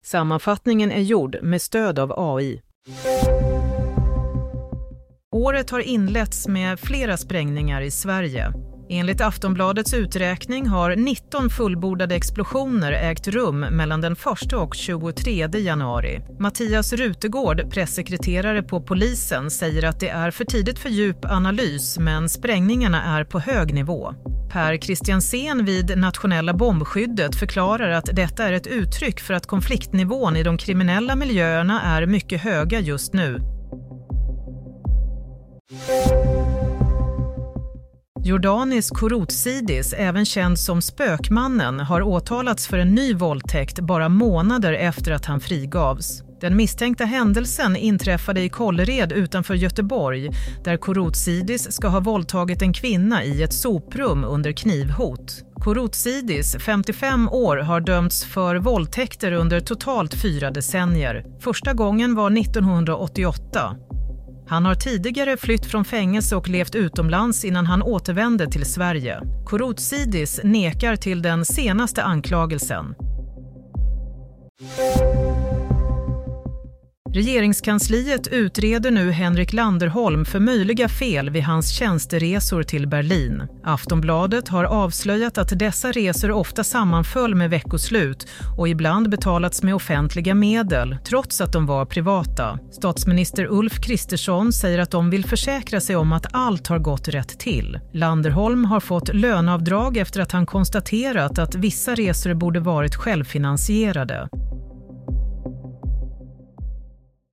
Nyhetssammanfattning - 23 januari 16.00